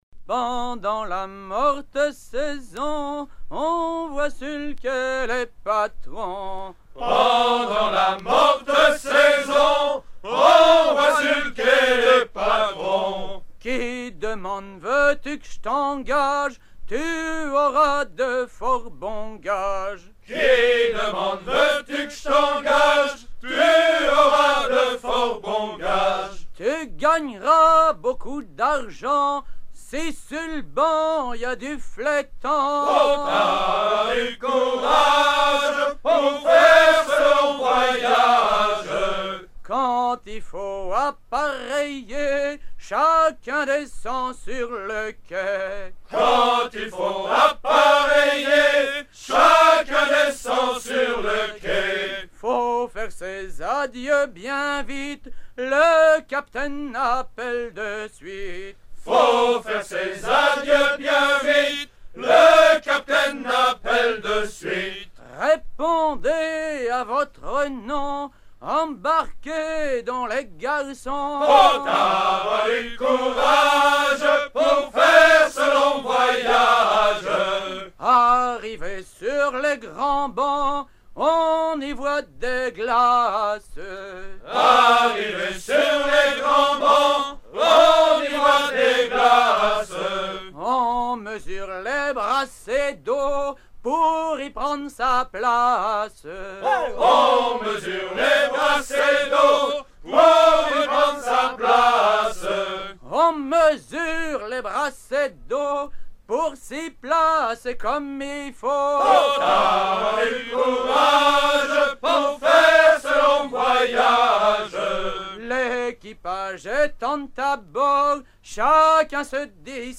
Version recueillie en 1975 et 1977 auprès de trois terre-nevas fécampois
gestuel : à virer au guindeau
circonstance : maritimes